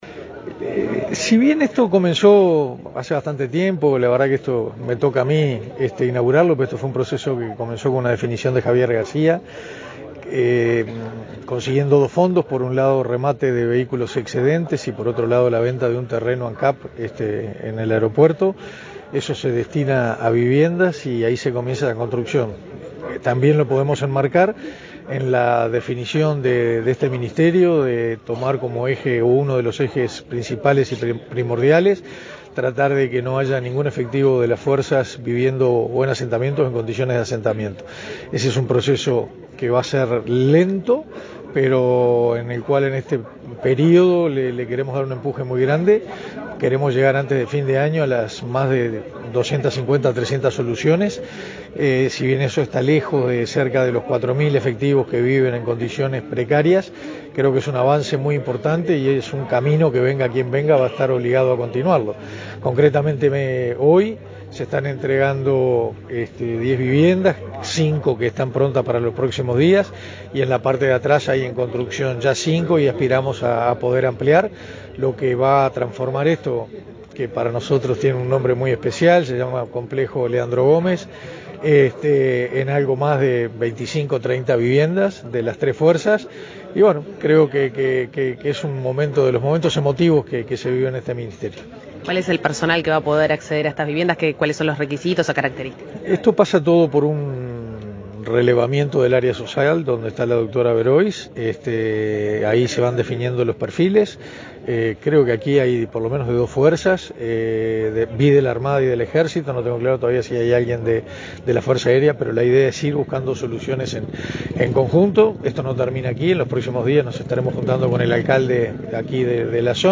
Entrevista al ministro de Defensa Nacional, Armando Castaingdebat